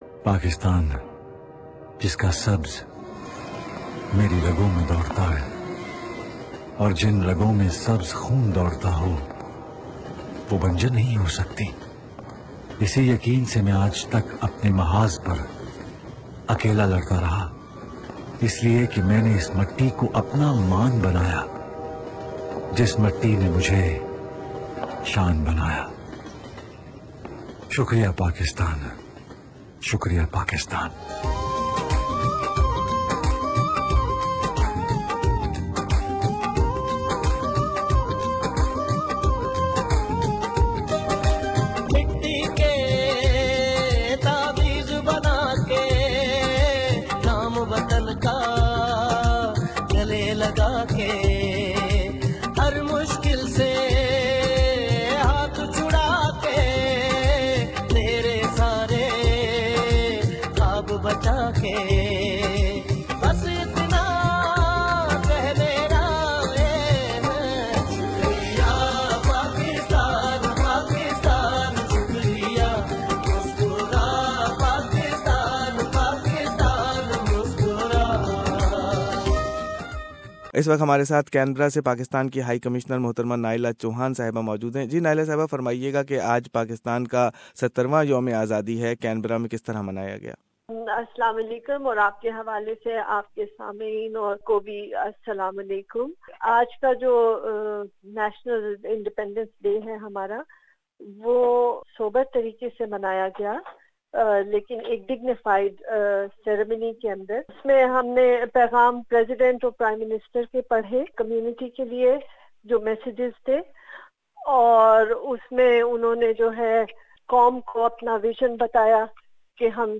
This year celebrations were sober with messages of condolence to the relatives and friends of those who lost their love ones in recent Quetta terror attack. Pakistani high commission and Pakistan consulate general has flag hoisting ceremonies. High Commissioner Naela Chohan, newly appointed Counsel General Abdul Majid Yousefani and Deputy Counsel General Bushra Salam spoke about Pakistan Independence Day events in Sydney and Canberra.